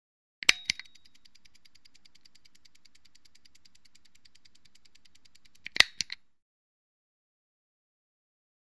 Misc. Sports Elements; Five Second Ticking With Start And Stop Click.